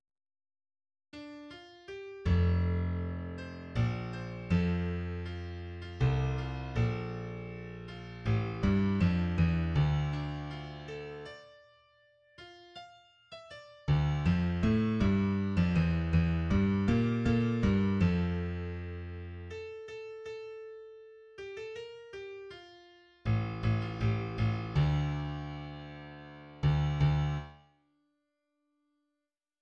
3rd verse